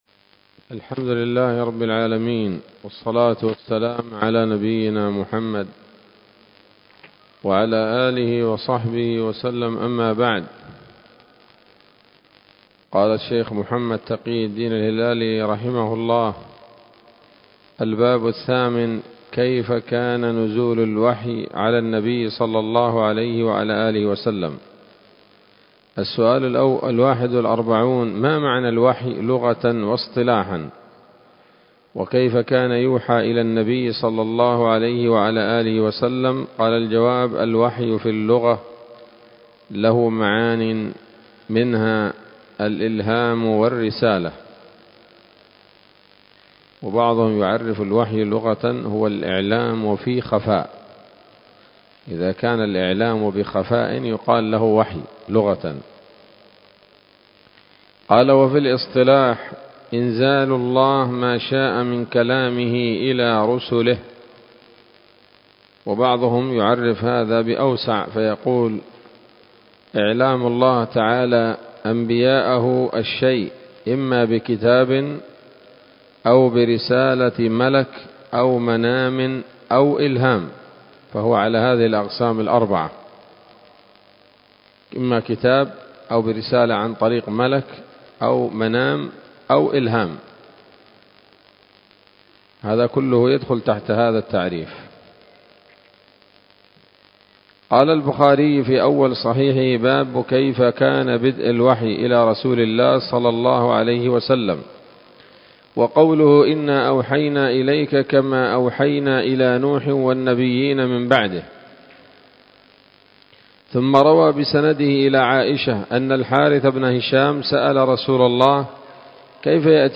الدرس الثالث عشر من كتاب نبذة من علوم القرآن لـ محمد تقي الدين الهلالي رحمه الله